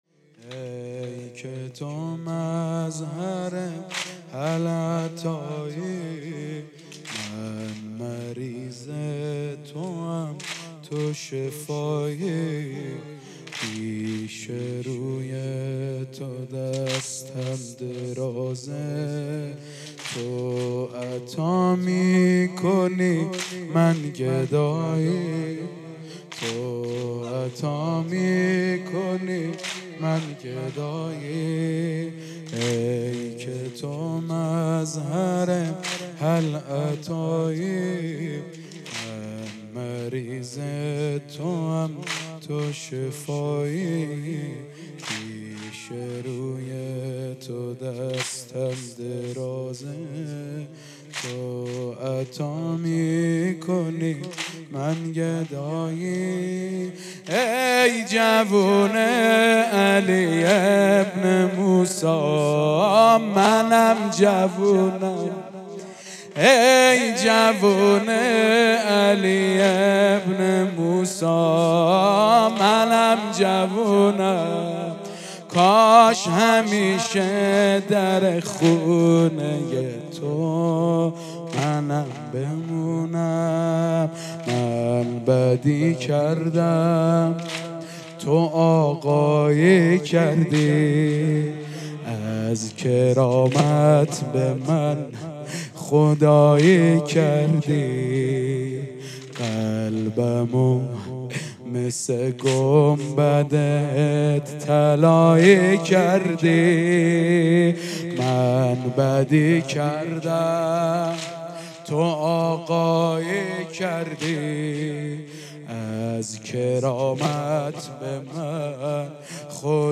مرثیه‌ سرایی
مداحی اهل بیت